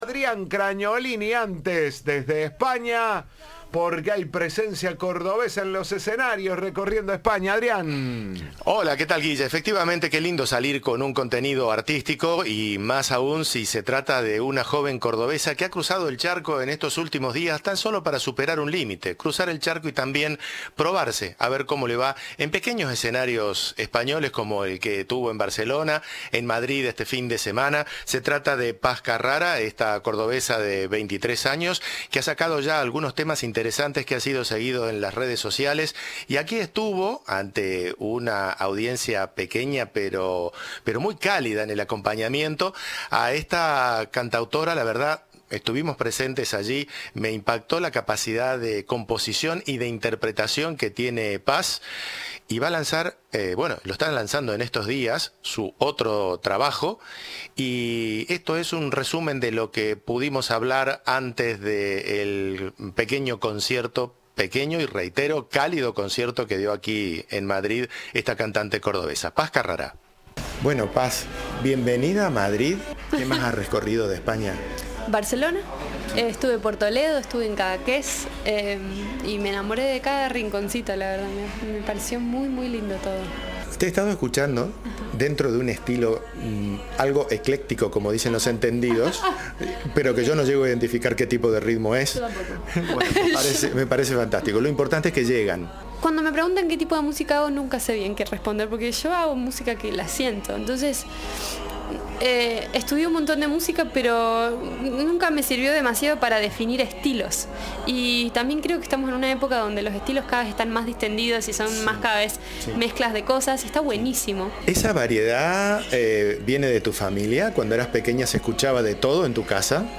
Antes de subirse a las tablas habló en exclusiva con Cadena 3 y señaló que estuvo en Barcelona, Toledo, Cadaqués y se enamoró "de cada rinconcito".